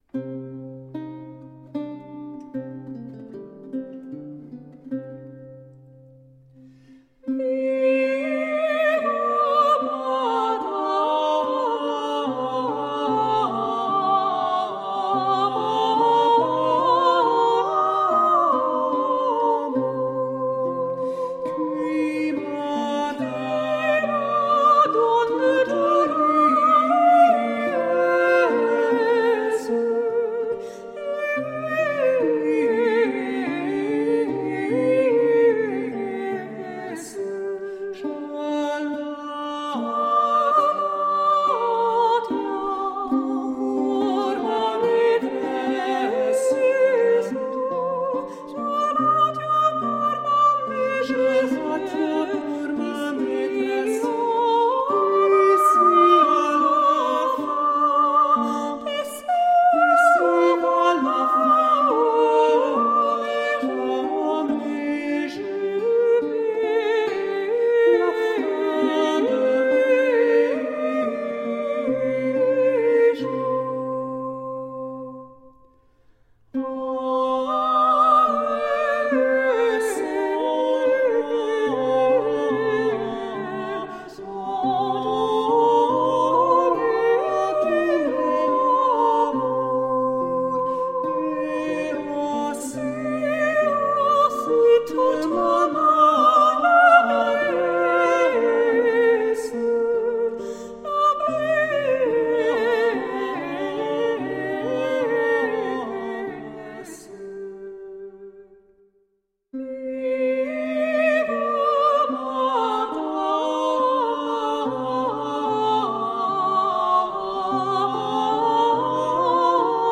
Late-medieval vocal and instrumental music.